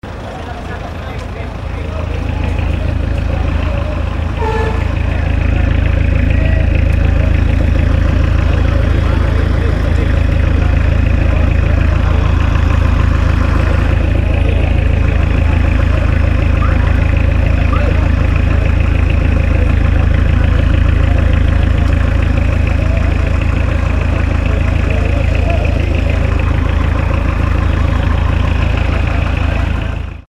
Ralentí de un autobús antiguo
Sonidos: Transportes
Sonidos: Ciudad